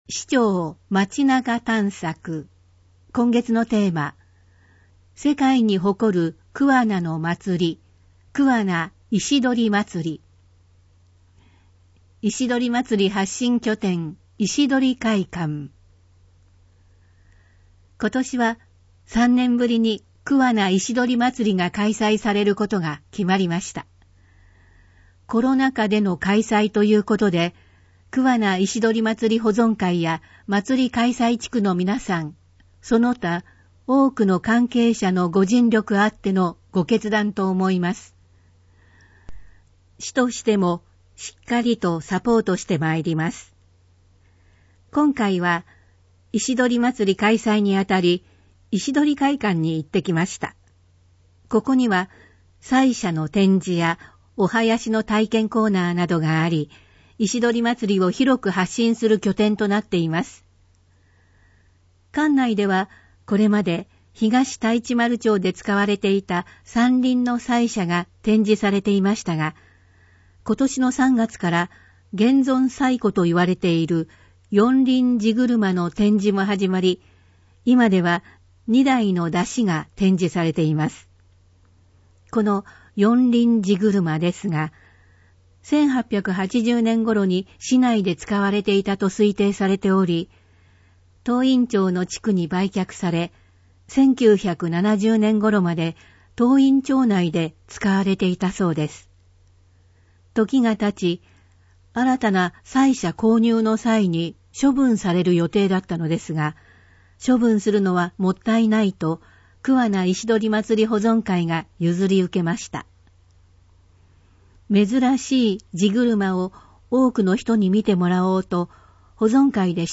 なお、「声の広報くわな」は桑名市社会福祉協議会のボランティアグループ「桑名録音奉仕の会」の協力で制作しています。